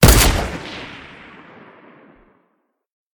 mgun1.ogg